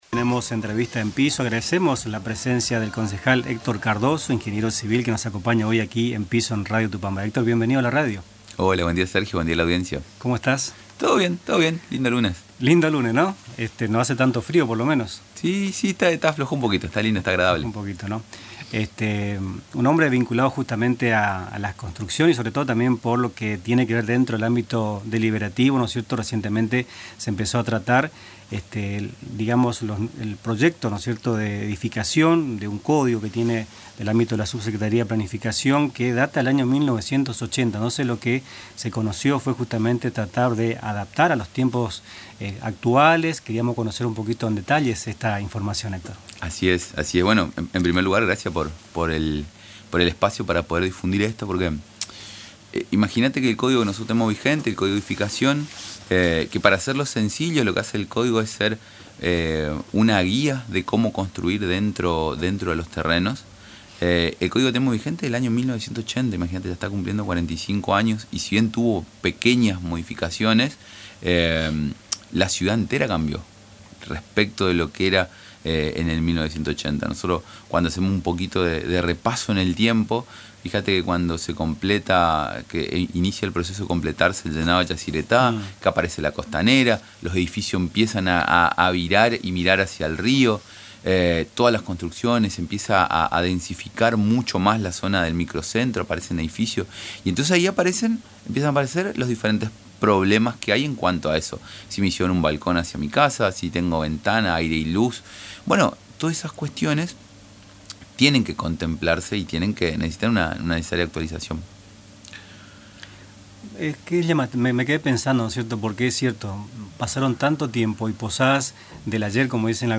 En diálogo con el programa Nuestras Mañanas de Radio Tupambaé, el concejal Héctor Cardozo (Frente Renovador), ingeniero civil de profesión, brindó detalles sobre el proceso de actualización y los principales ejes del nuevo instrumento legal.